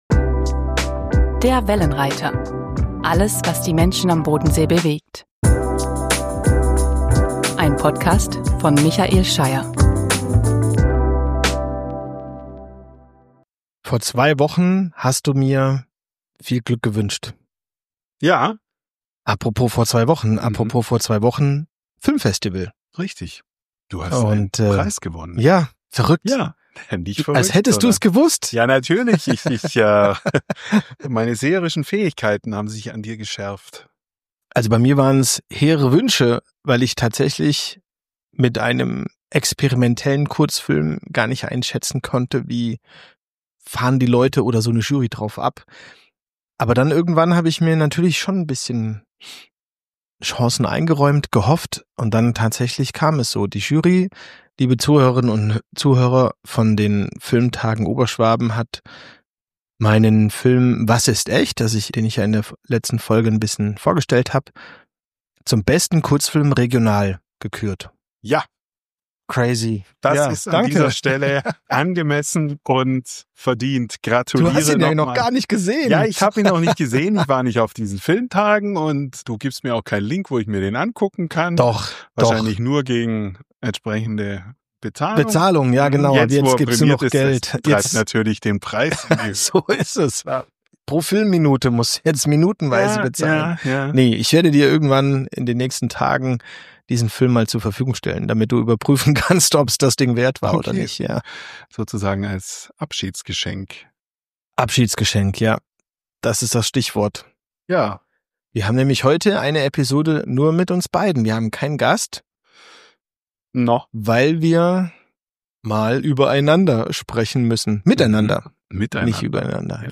In der 20. Ausgabe haben sie allerdings keinen Gast eingeladen, sondern sprechen miteinander über das, was sie zusammen erlebt haben. Sie erinnern sich an die spannendsten, lustigsten und nachdenklichsten Gesprächsmomente. Sie denken darüber nach, was die Magie des Podcastens ist.